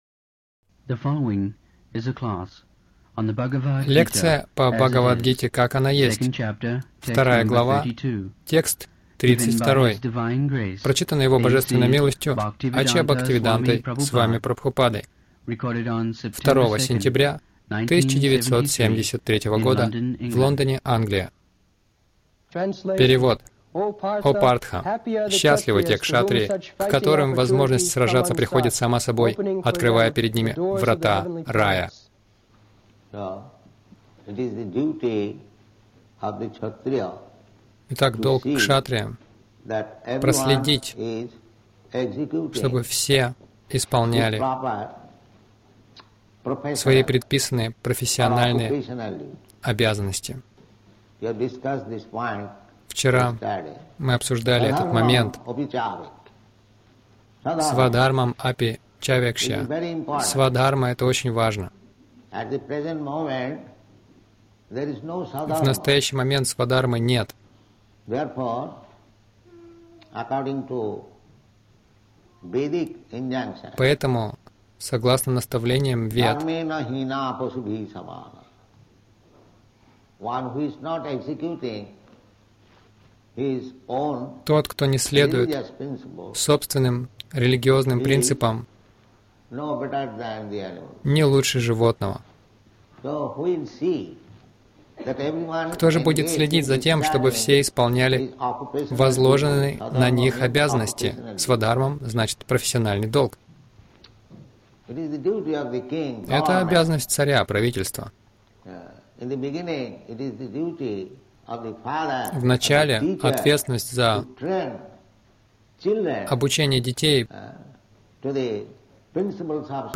Милость Прабхупады Аудиолекции и книги 02.09.1973 Бхагавад Гита | Лондон БГ 02.32 — Правительство должно быть строгим Загрузка...